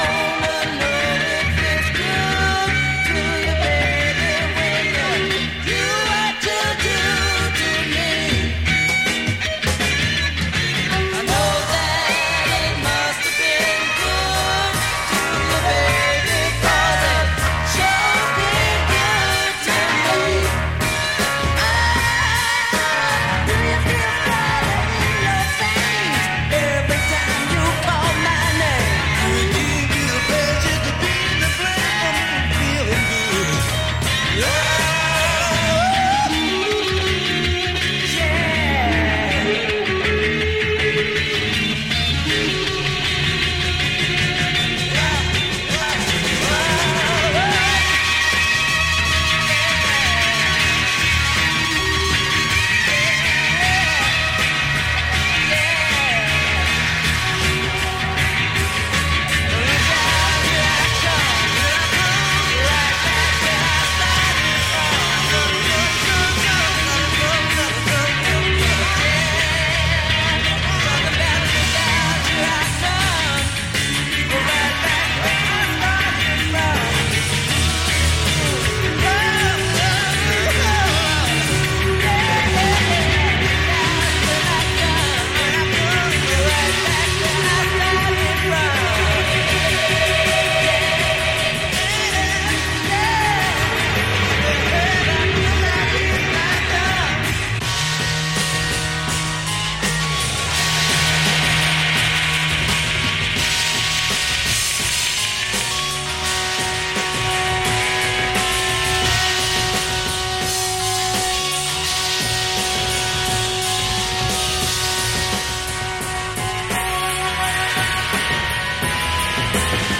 Funk / soul
Psychedelic rock